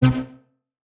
Synth Stab 04 (C).wav